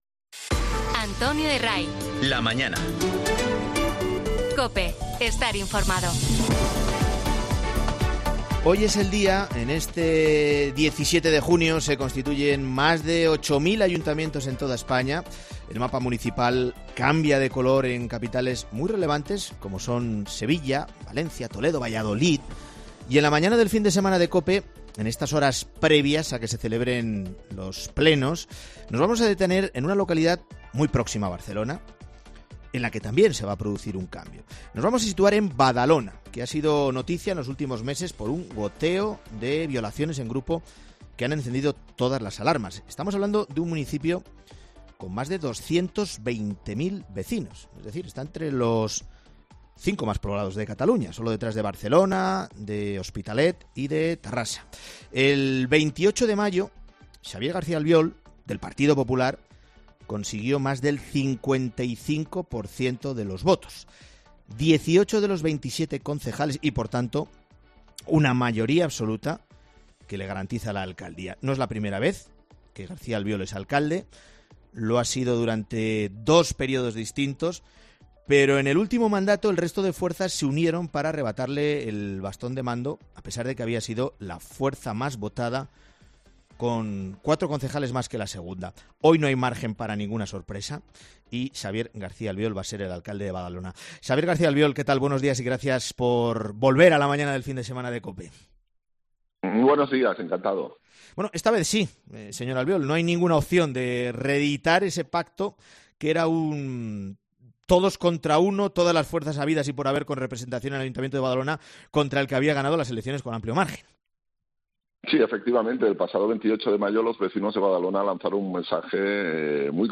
No obstante, el nuevo alcalde de Badalona asegura en 'La Mañana Fin de Semana' que los resultados de las municipales 28 de mayo, "marcan una tendencia"